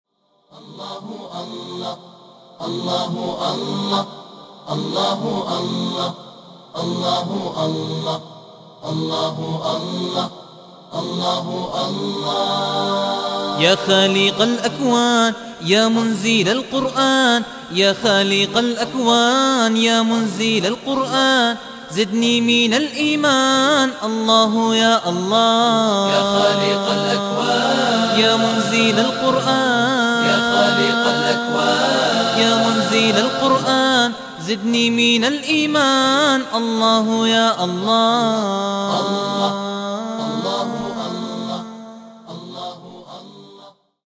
من هو هذا المنشد :